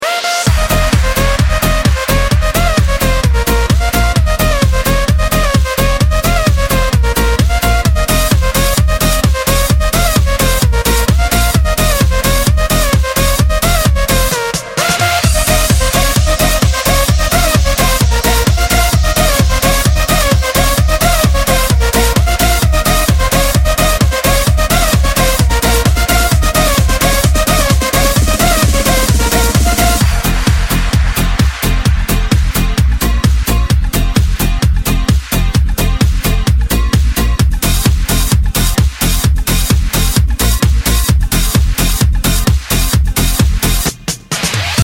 Оригинальная обработка старой русской песни